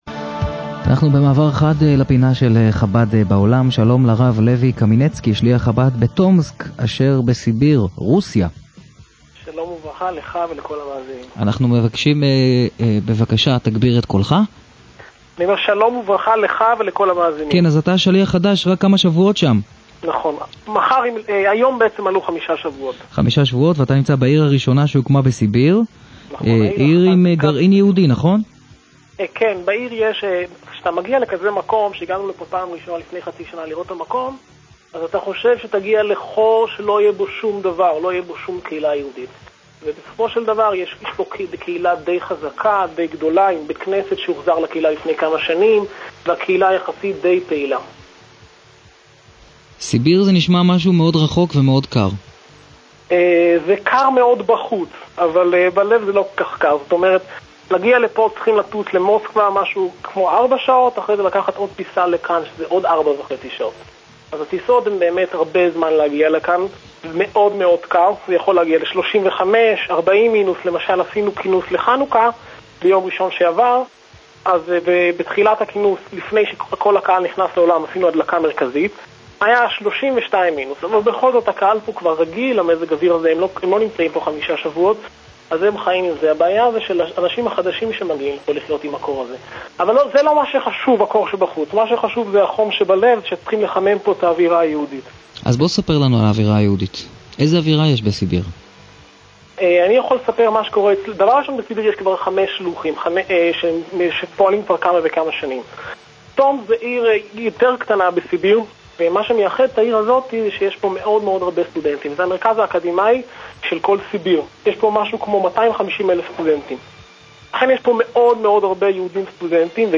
אחרי שעשרות שלוחים ותיקים התראיינו בפינת השליח בתכנית "קול שישי" ברדיו "קול-חי"